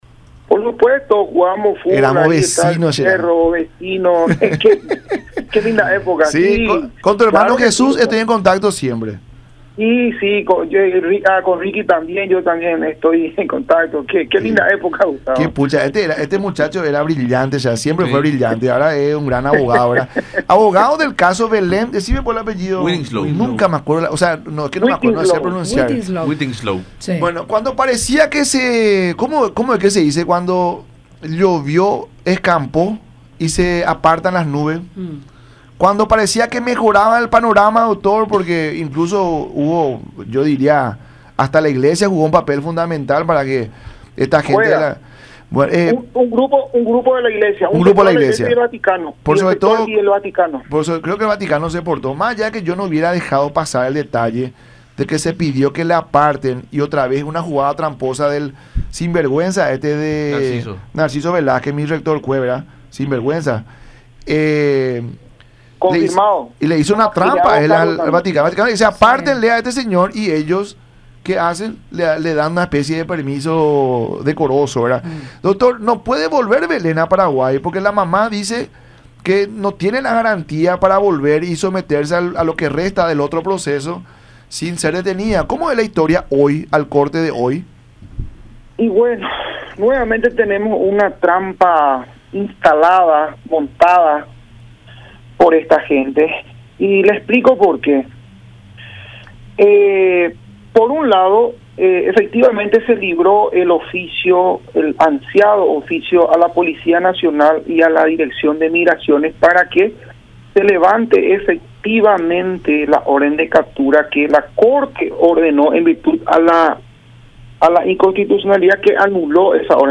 en diálogo con La Mañana De Unión por Unión TV y radio La Unión